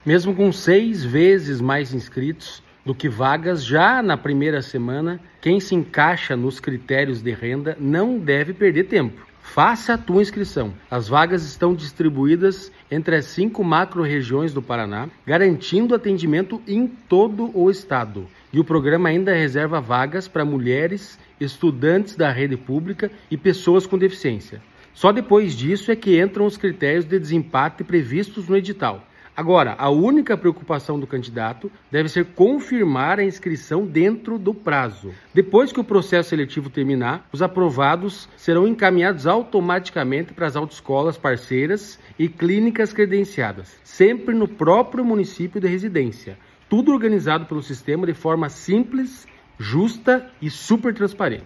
Sonora do presidente do Detran-PR, Santin Roveda, sobre as inscrições para o programa CNH Social